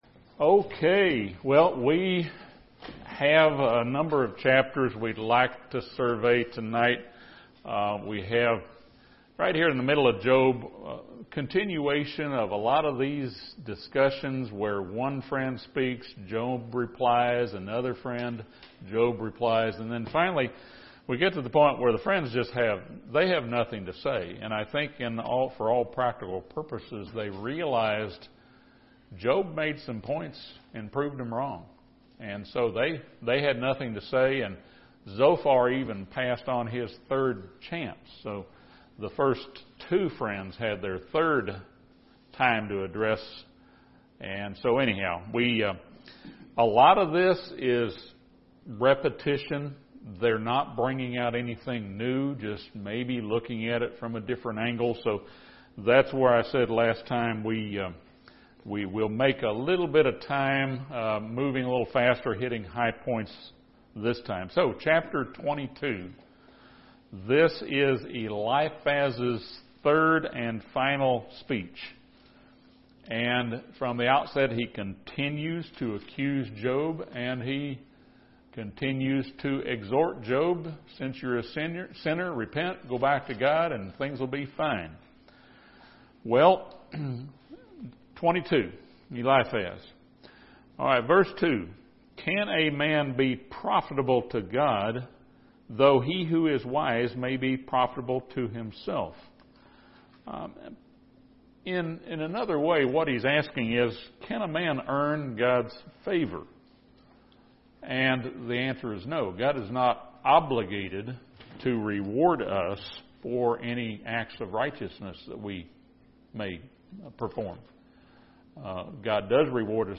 This Bible study surveys Job chapters 22-29.